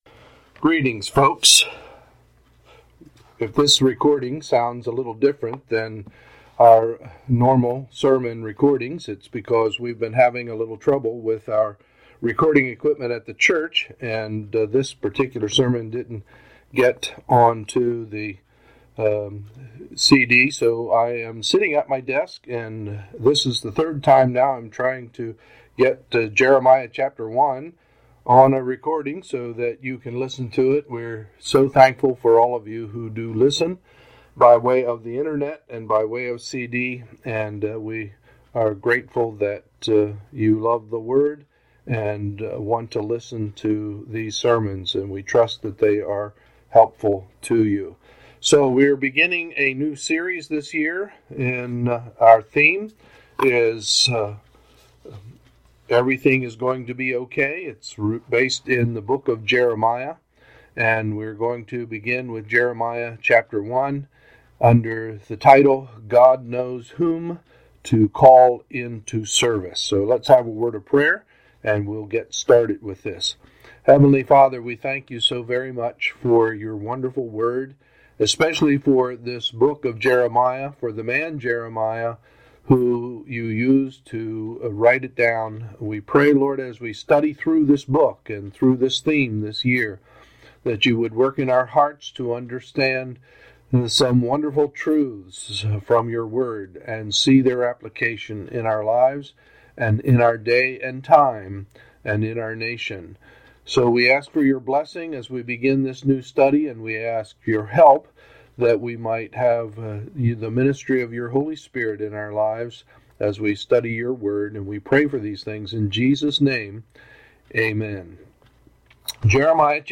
Sunday, January 11, 2015 – Sunday Morning Service